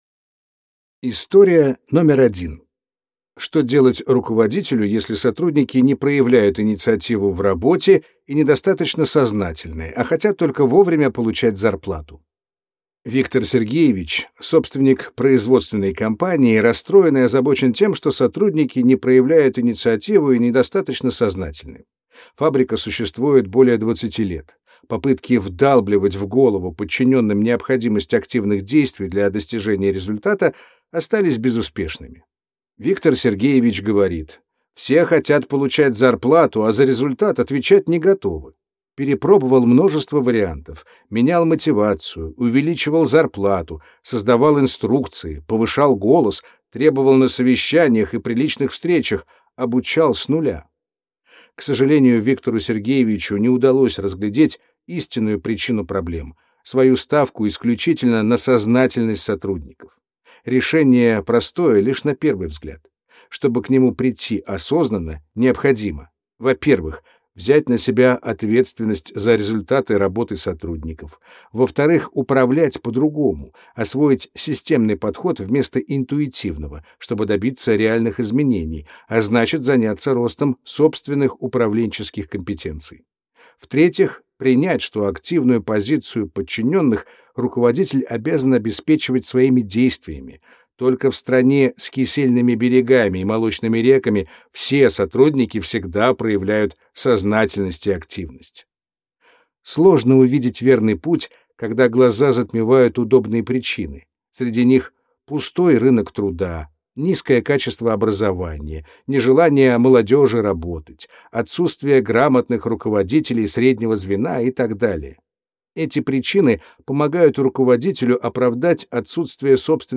Аудиокнига Системное управление на практике. 50 историй из опыта руководителей для развития управленческих навыков | Библиотека аудиокниг